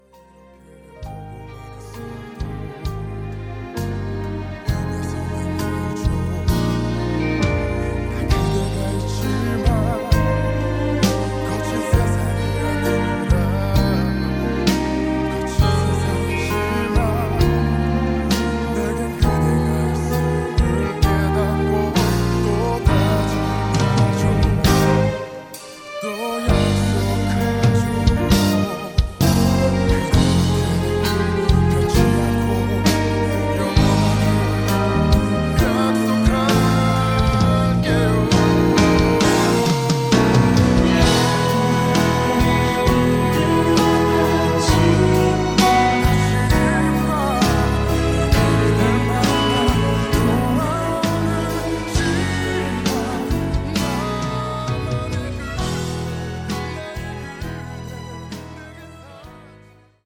음정 -1키 5:37
장르 가요 구분 Voice MR